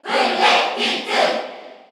Category: Crowd cheers (SSBU) You cannot overwrite this file.
Dark_Pit_Cheer_Korean_SSBU.ogg